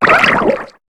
Cri de Nucléos dans Pokémon HOME.